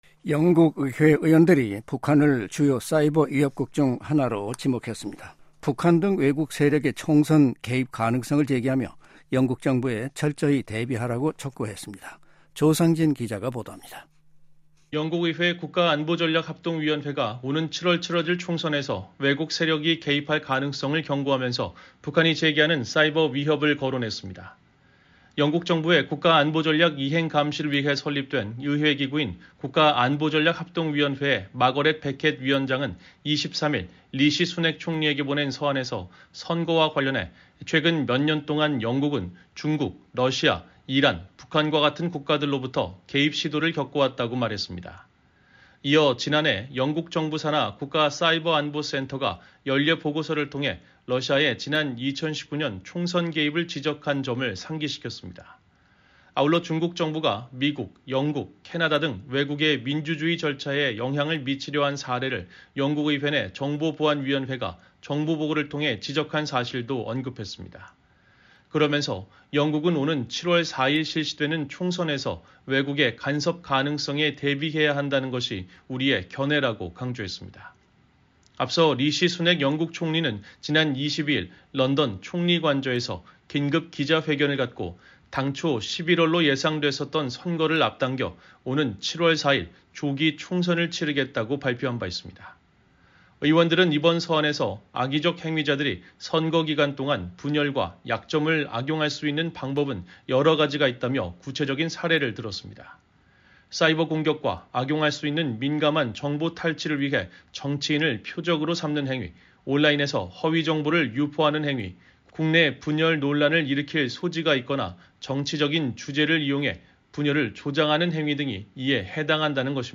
정치·안보